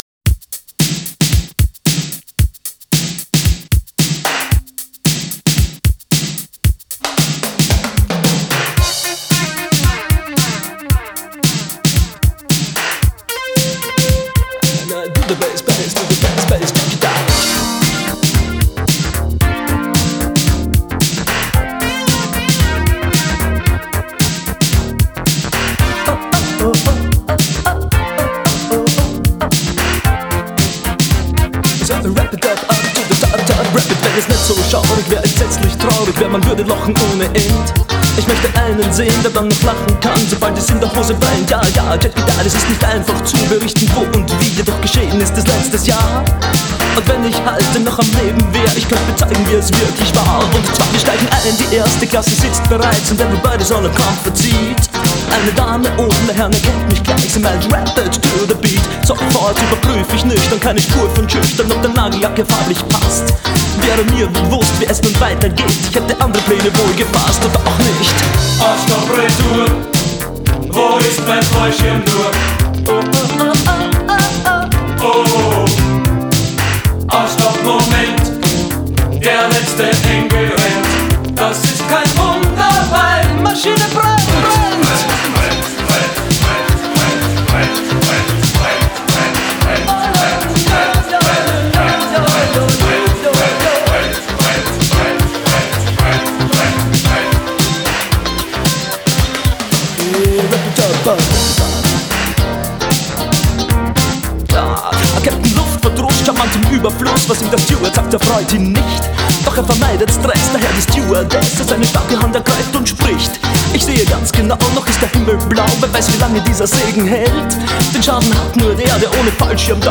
Genre: Rock,New Wave